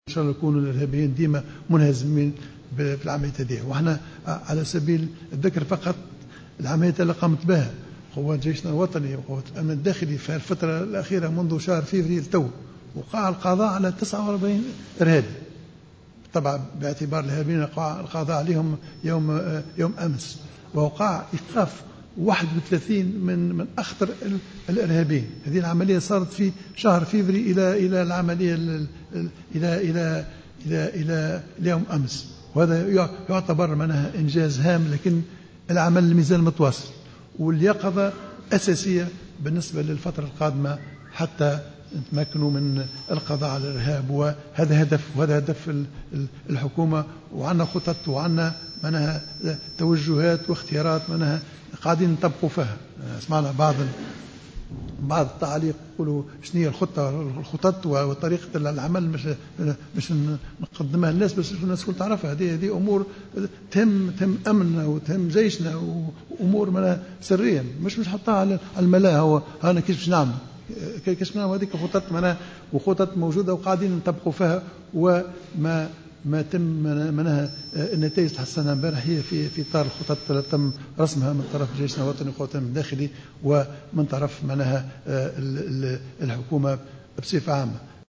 Lors d'une conférence de presse tenue ce mardi 8 mars 2016, et ayant porté sur les événements survenus hier à Ben Guerdane, Essid a ajouté que 31 autres terroristes ont été arrêtés, saluant le courage et la bravoure des forces sécuritaires et militaires.